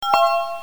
SFX叮叮合乎音效下载
SFX音效